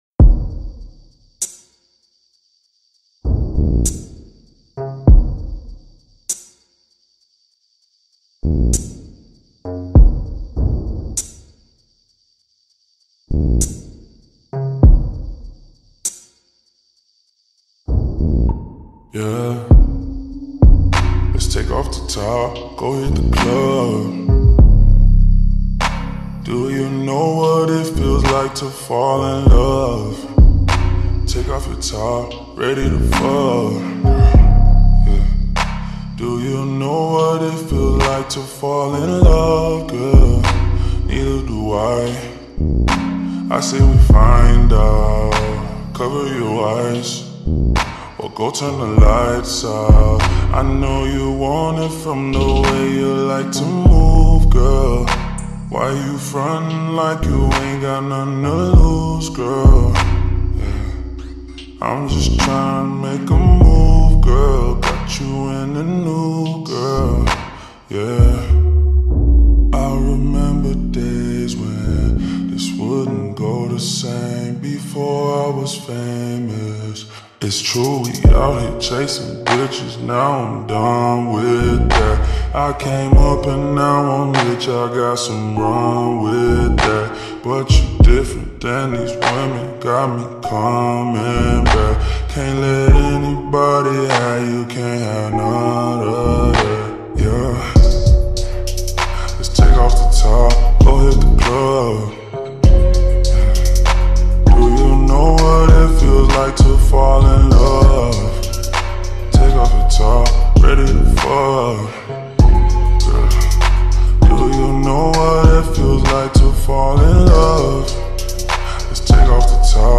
s l o w e d + r e v e r b